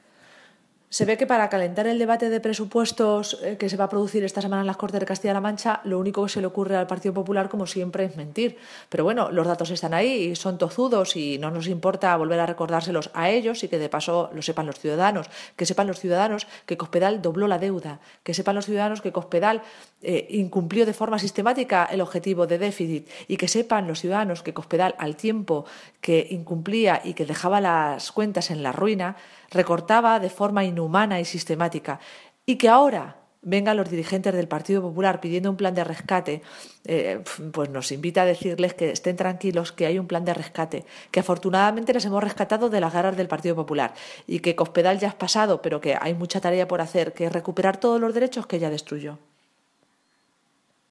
La portavoz regional del PSOE, Cristina Maestre, asegura que las cuentas para este año se centran en las personas "son realistas y hechas con rigor no como las del anterior ejecutivo"
Cortes de audio de la rueda de prensa